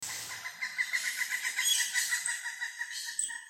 Tucán Toco (Ramphastos toco)